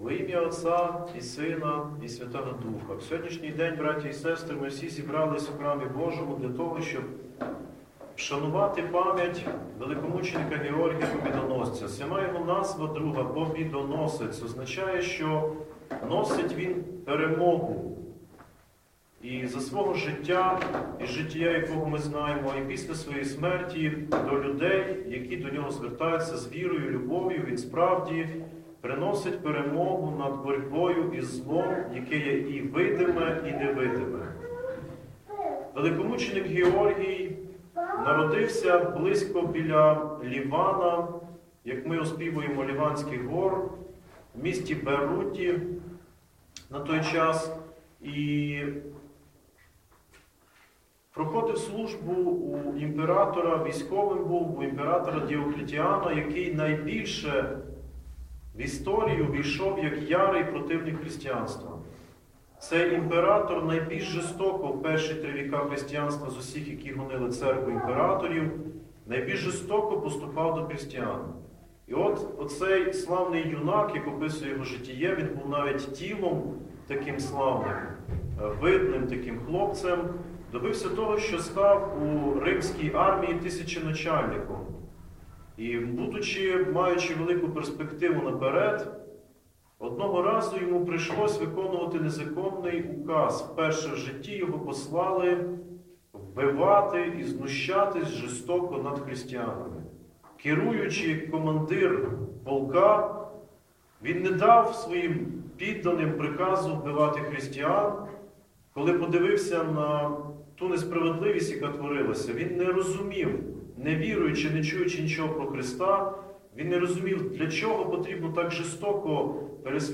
Проповідь у День Памяті великомученика Георгія Побідоносьця – Храм Святителя Іоанна Шанхайського і Сан-Франциського м. Ужгорода